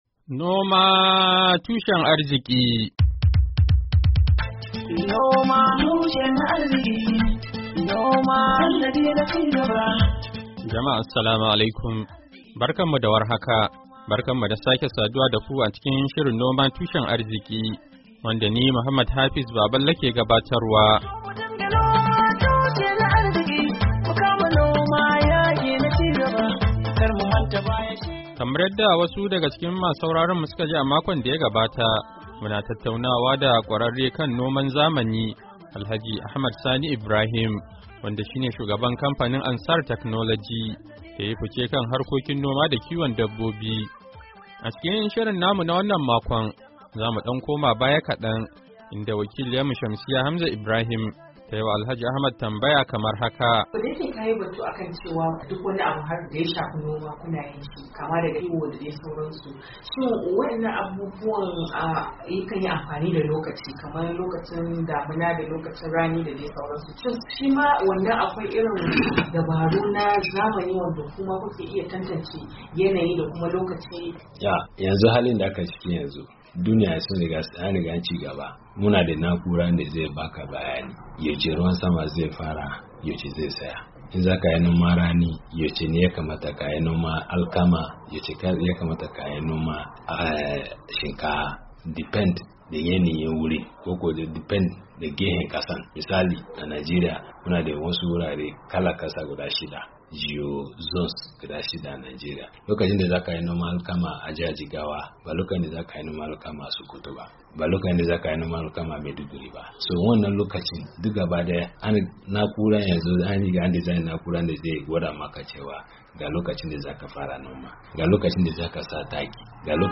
NOMA TUSHEN ARZIKI: Hira Da Kwararre Kan Noma Da Kiwon Dabbobi Na Zamani a Najeriya - Kashi Na Biyu - Janairu 10, 2023